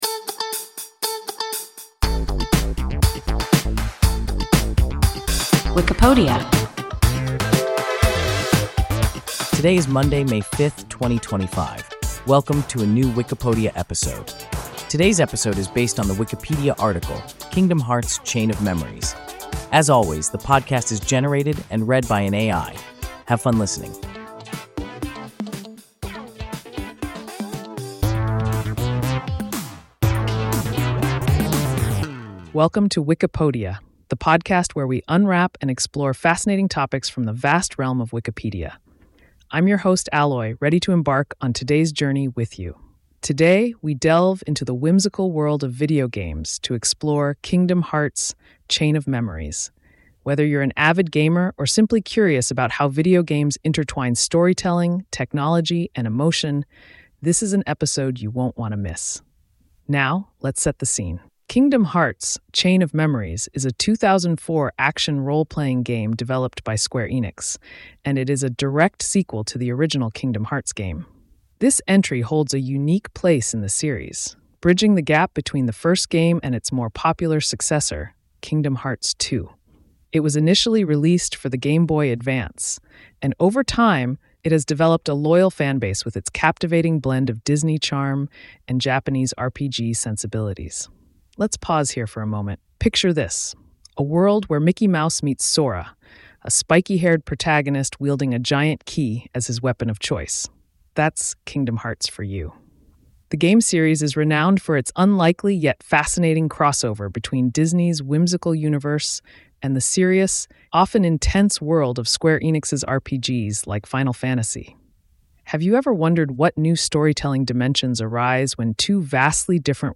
Kingdom Hearts: Chain of Memories – WIKIPODIA – ein KI Podcast